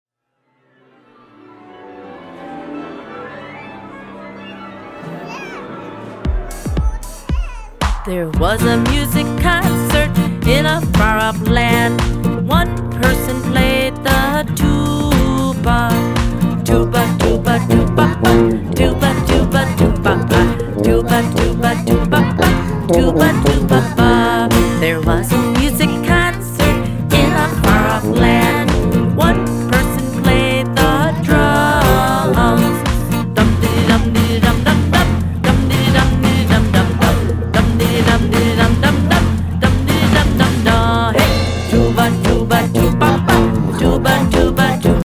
recorded in a small recording studio